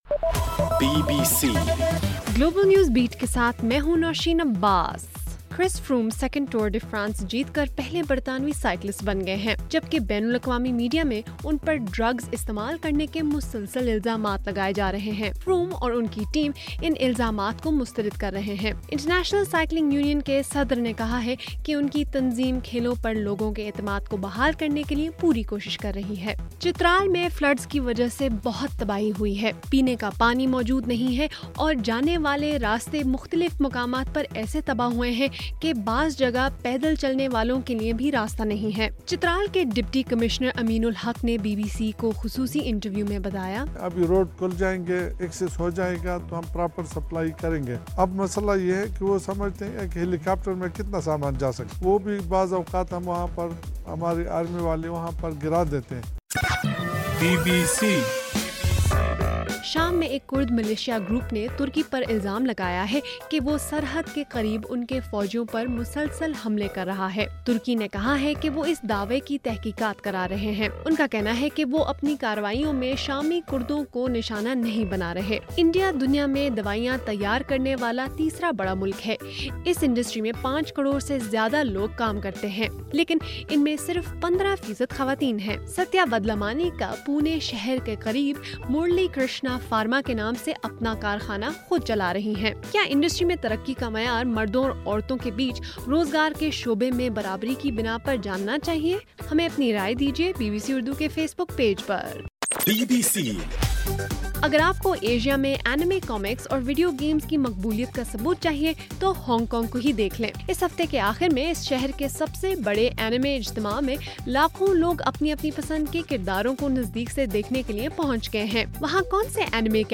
جولائی 27: رات 12بجے کا گلوبل نیوز بیٹ بُلیٹن